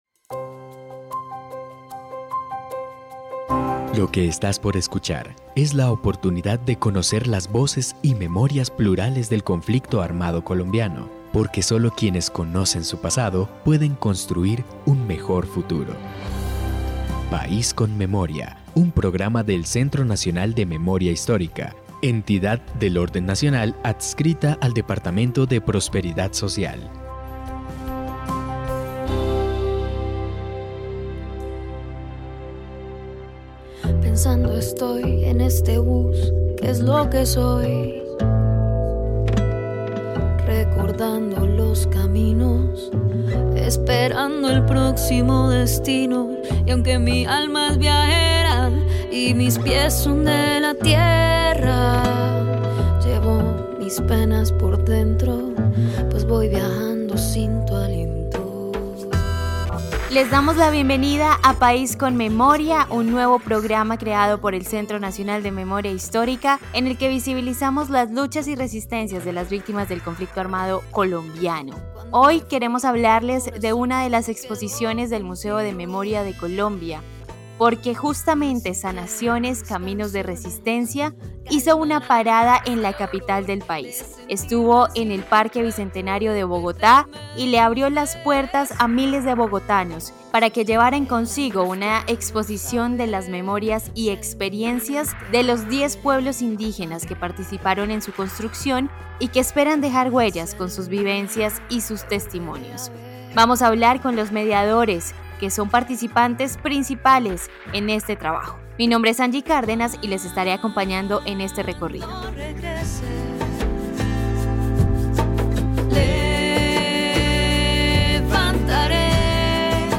En País con Memoria hablamos con algunos de sus mediadores, quienes comparten sus experiencias de aprendizaje y socialización de la exposición.
Descripción (dcterms:description) Capítulo número 22 de la cuarta temporada de la serie radial "País con Memoria".